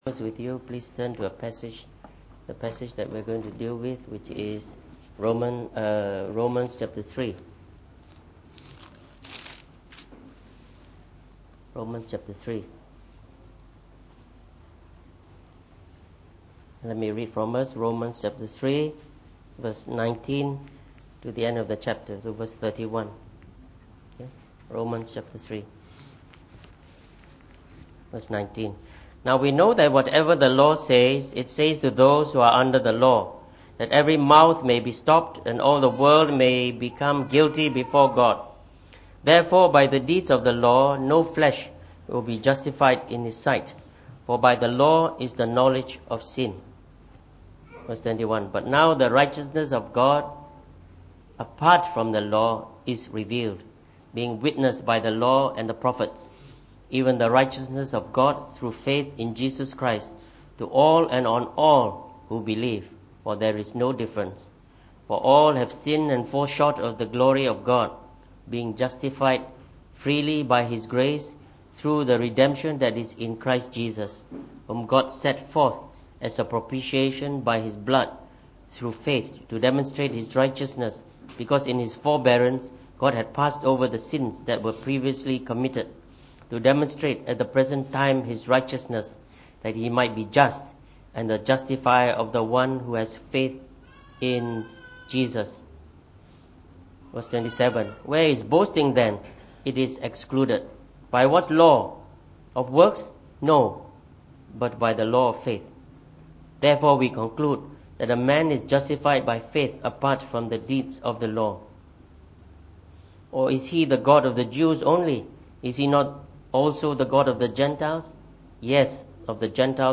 From our series on the Book of Romans delivered in the Morning Service.